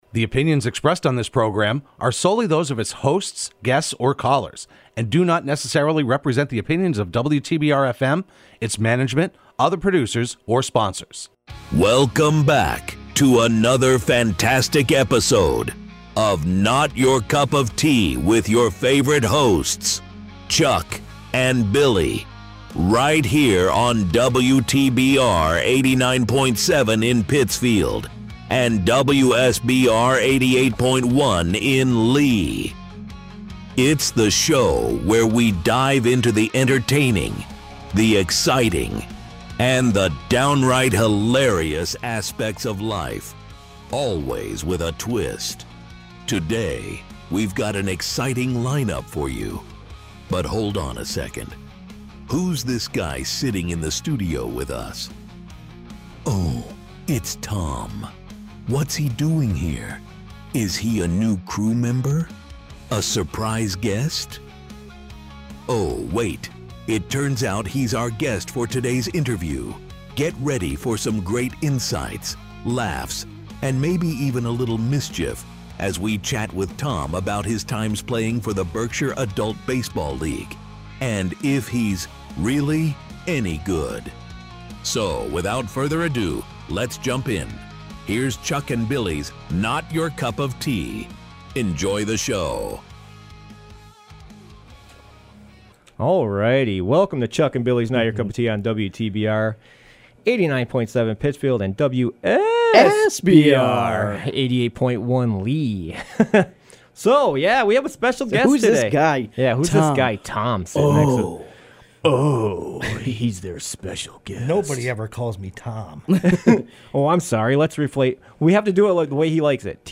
Broadcast live every Wednesday afternoon at 4pm on WTBR.